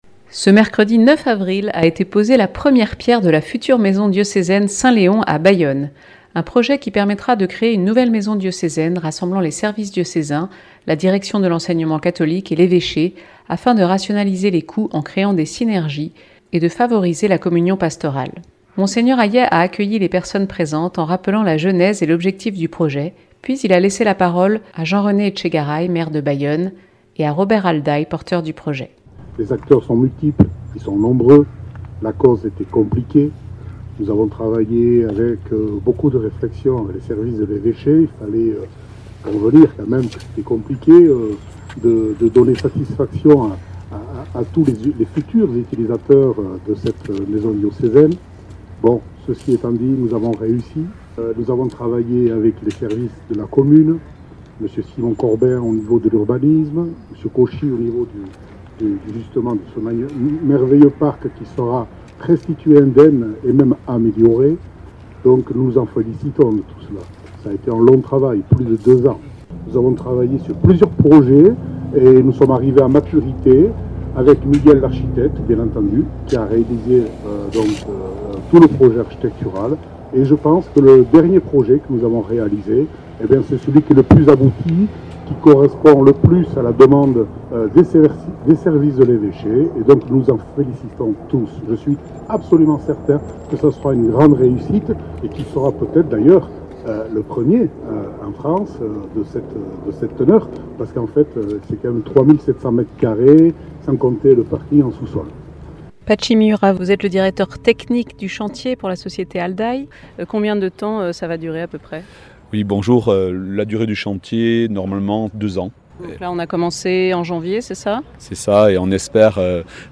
Reportage le 9 avril 2025 sur le site de Saint-Léon de Marracq.